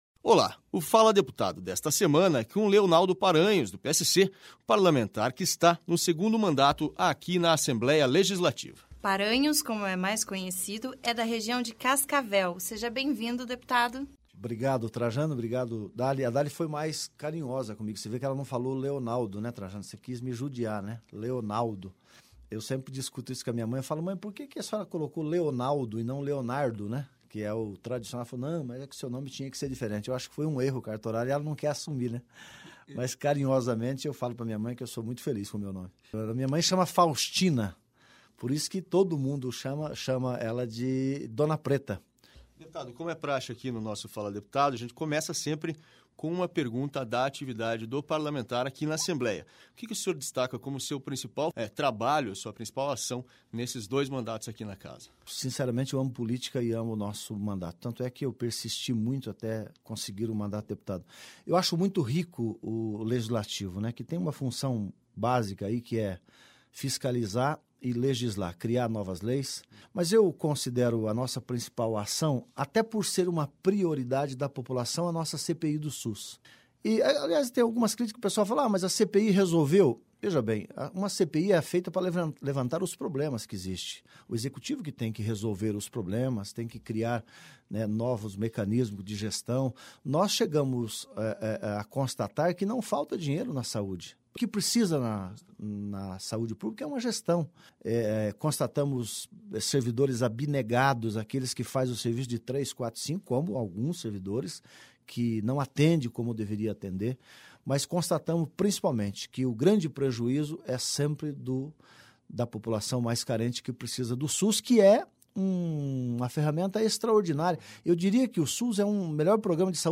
Na entrevista, ele contou boas histórias do tempo de vendedor e de como a persistência o ajudou a se tornar parlamentar.